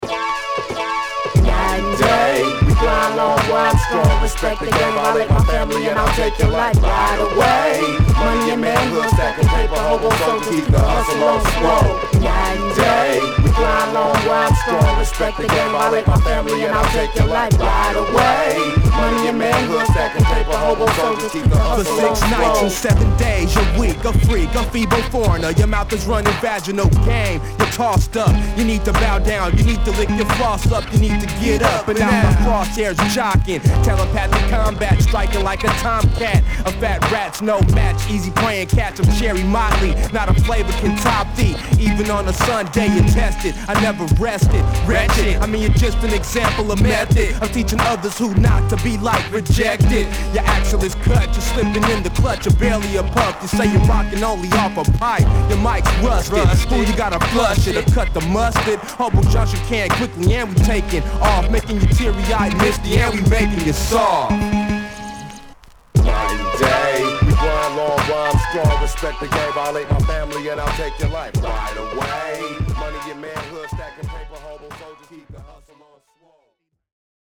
・ HIP HOP UNDERGROUND 12' & LP